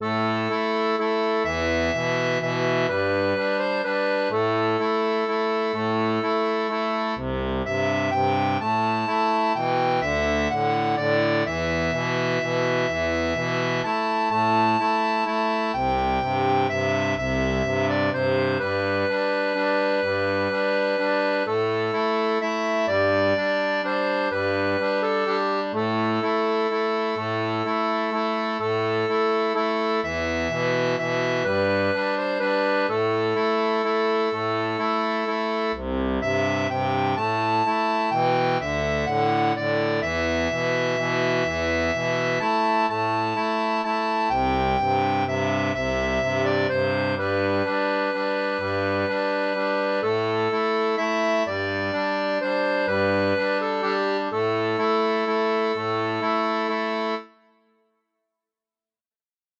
Inspiré d’un air traditionnel anglais
Musique traditionnelle